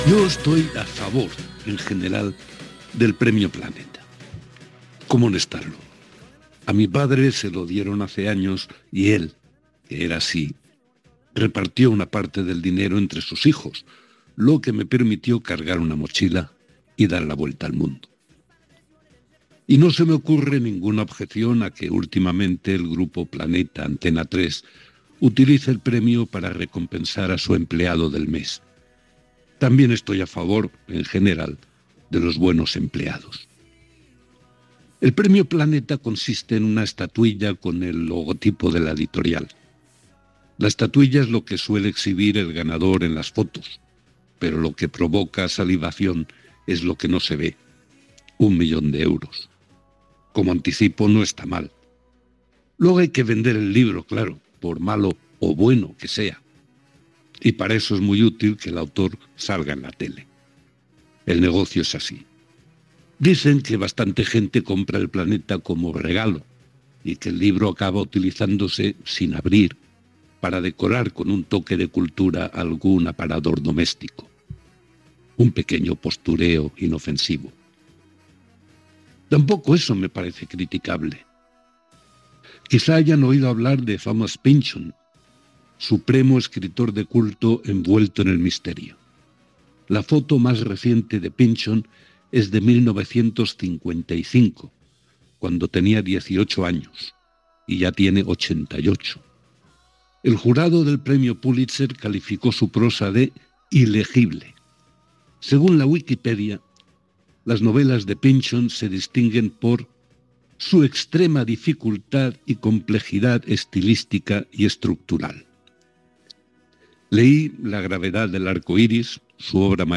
Secció "La píldora" del periodista Enric González, sobre el Premi Planeta.
Entreteniment
Programa presentat per Javier del Pino.